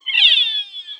AV_deer_howl.wav